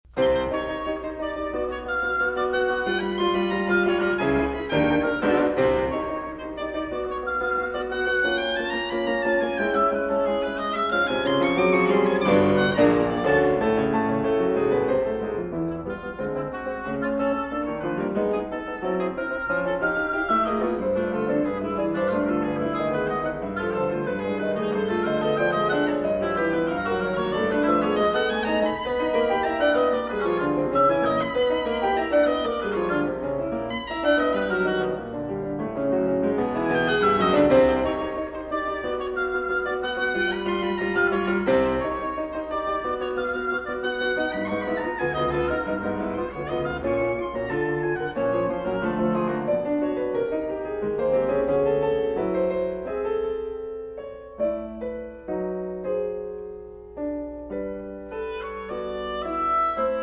Piano
Oboe